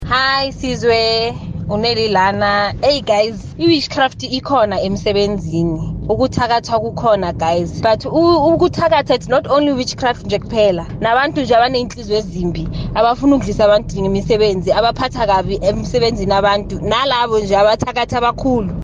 Kaya Drive listeners shared their opinions on witchcraft in the workplace: